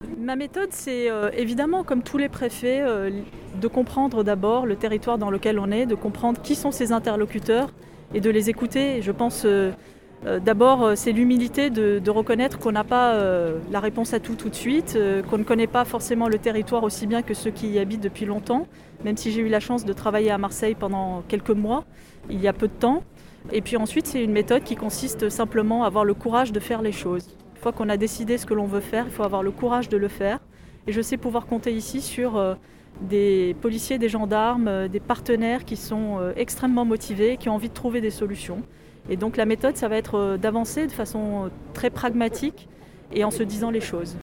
A l’issue d’une cérémonie de prise de fonction dans les formes, avec un dépôt de gerbe au monument des policiers morts du département des Bouches-du-Rhône, un passage en revue des troupes, discours… la nouvelle préfète de police des Bouches du Rhône a rencontré la presse.